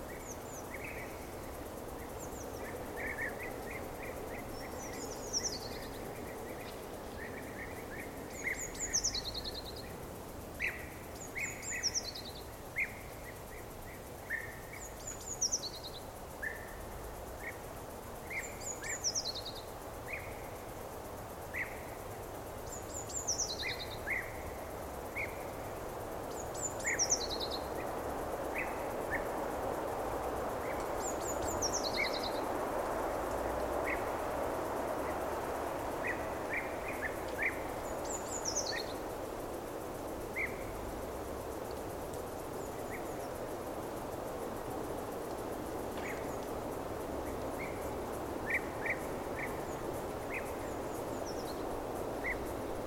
forest-birds.mp3